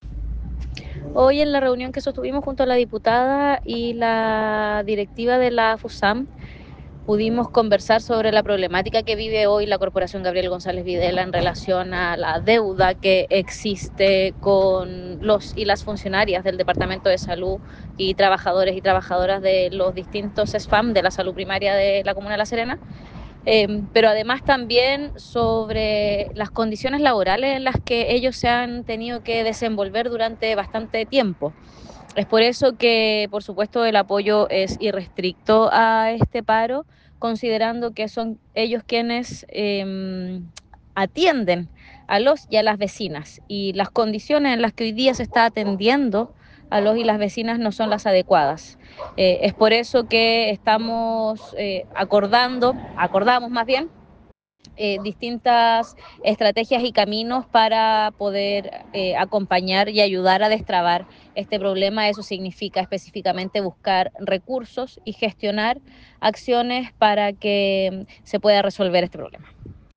En tanto, para la concejala Pojovmosky,